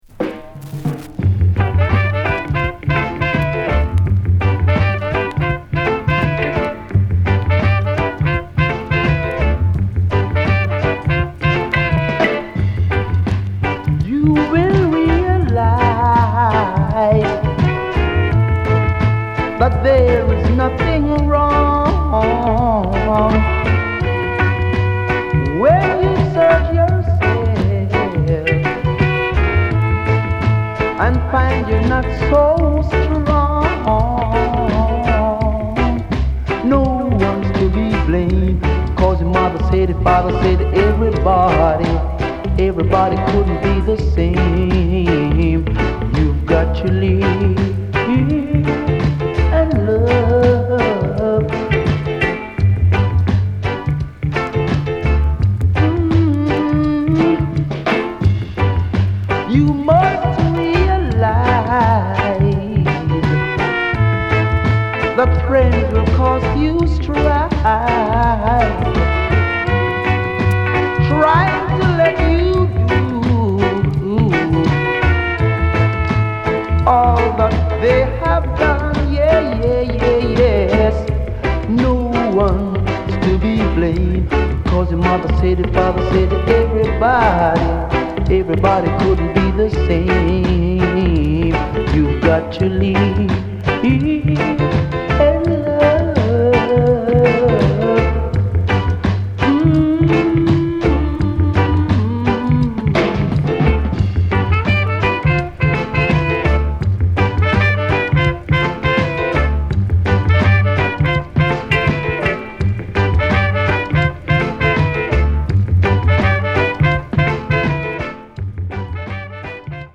緩やかでメロディアスなロック・ステディ・サウンドに乗せ、ポジティブなメッセージを歌う代表曲の1つ！